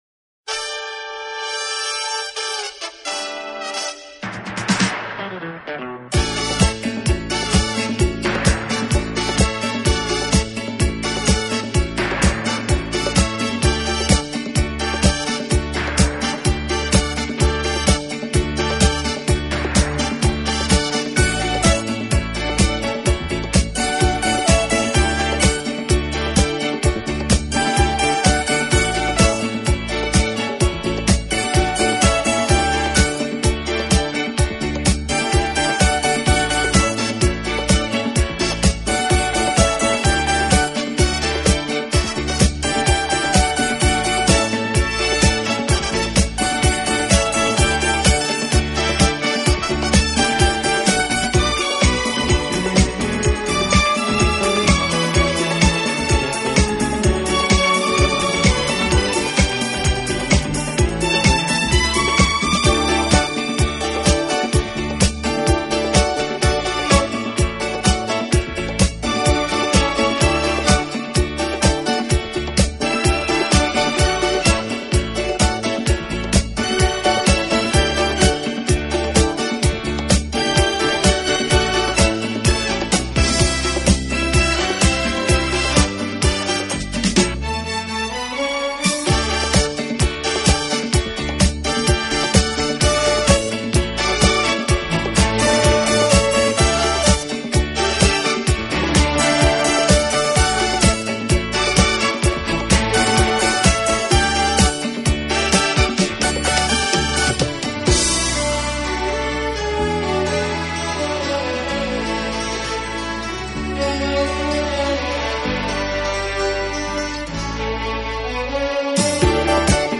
轻音乐
好处的管乐组合，给人以美不胜收之感。